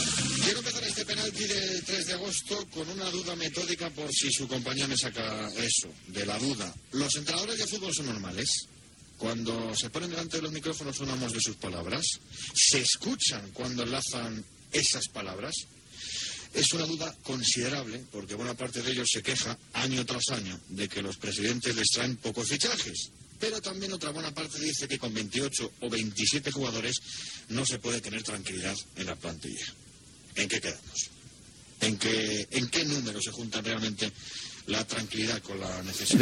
Inici del programa.
Esportiu
FM